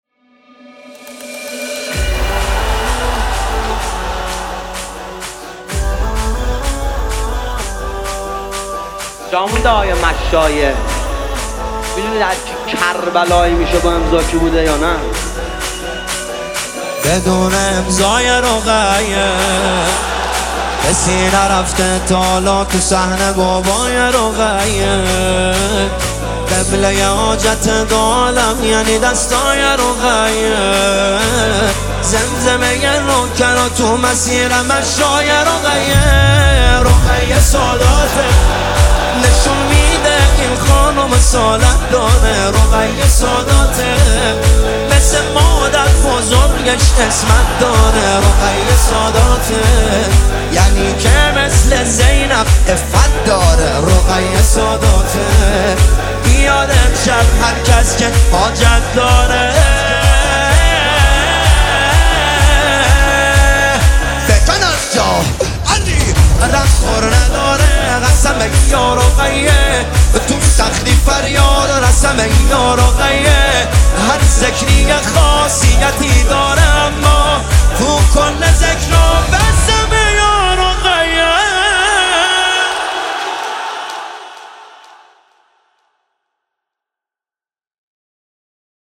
استودیویی